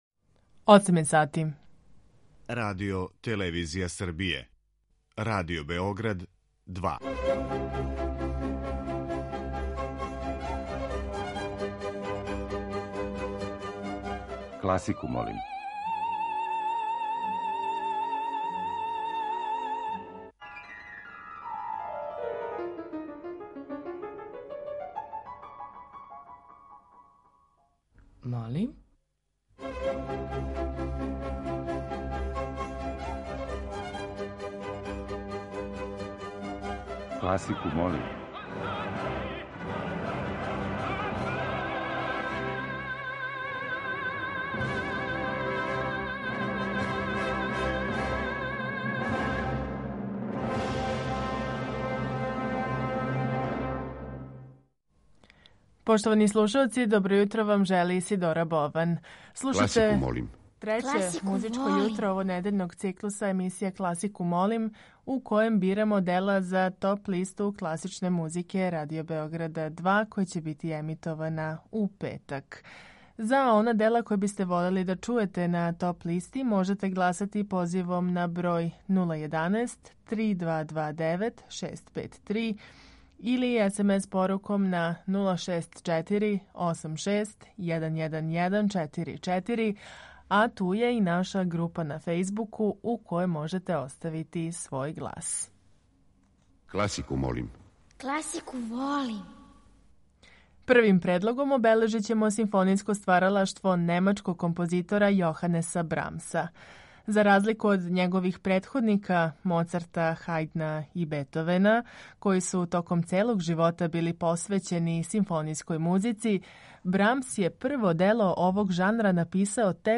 Уживо вођена емисија у којој се могу чути стилски разноврсна остварења класичне музике окренута је широком кругу слушалаца.